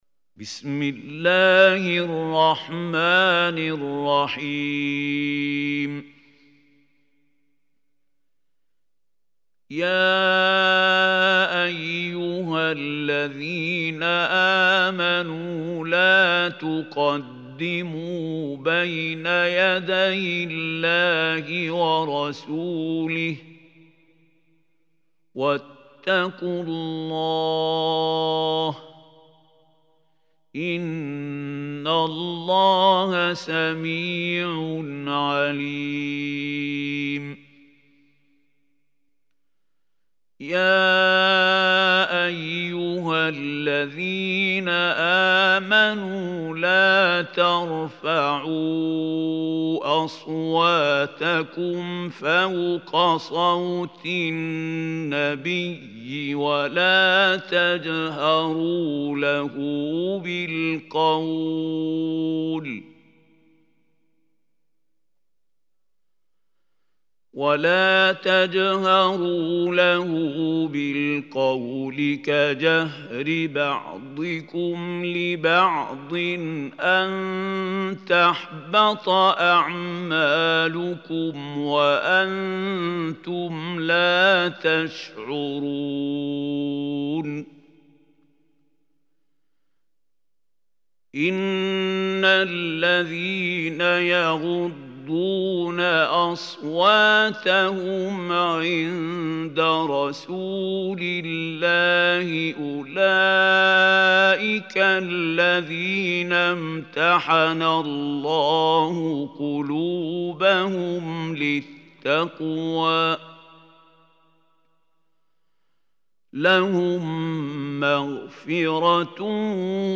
Surah Hujurat Recitation by Mahmoud Khalil Hussary
Surah Hujurat is 49 surah of Holy Quran. Listen or play online mp3 tilawat / recitation in Arabic in the beautiful voice of Sheikh Mahmoud Khalil Al Hussary.